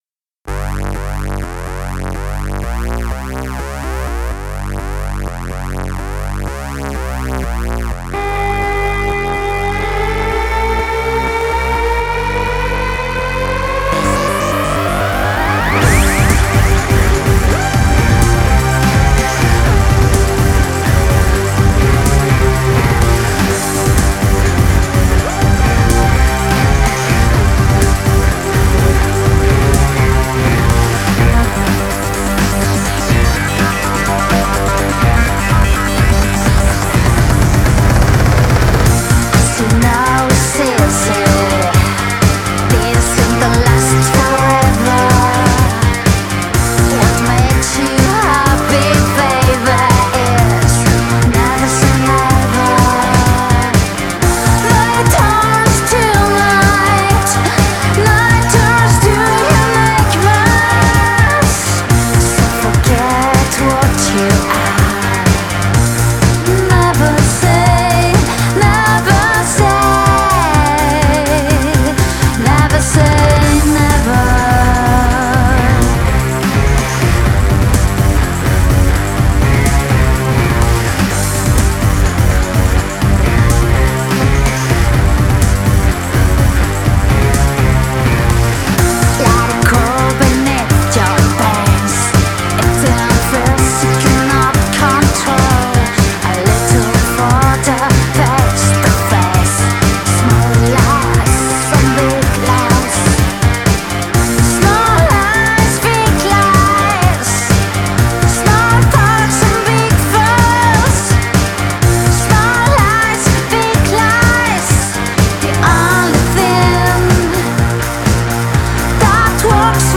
Electro-vintage, si claro !